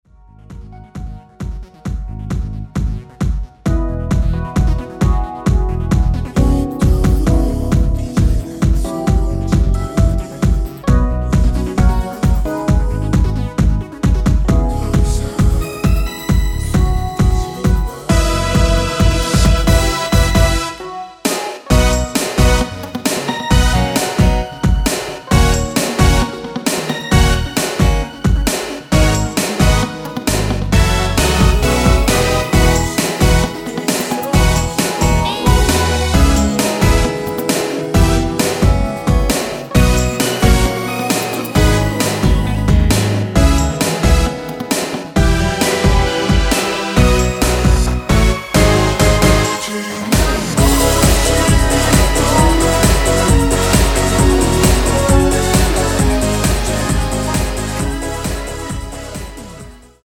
원키에서(-3)내린 코러스 포함된 MR입니다.
앞부분30초, 뒷부분30초씩 편집해서 올려 드리고 있습니다.
중간에 음이 끈어지고 다시 나오는 이유는